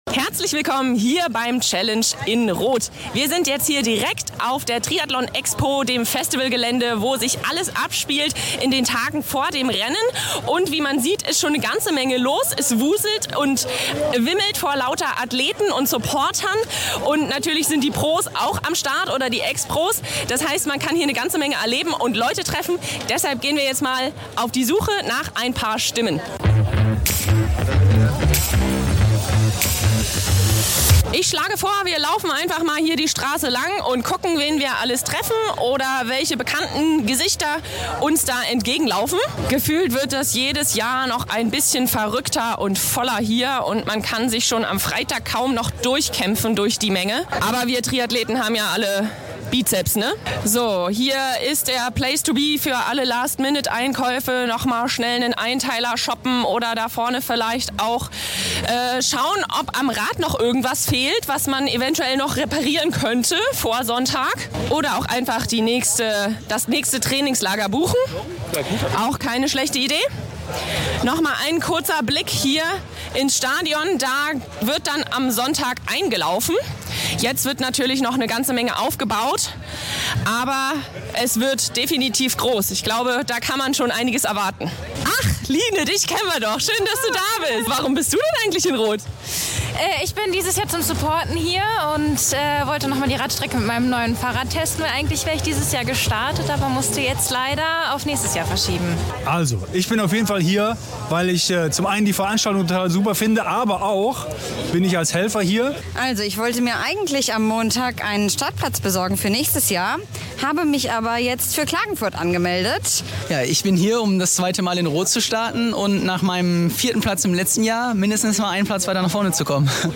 Stimmen vom Challenge Roth - Roth Special - MTP Folge 154 ~ Mission Triathlon Podcast
Wir sind aktuell beim Challengen in Roth, dem größten Triathlon
Age-Group-Athleten gefragt.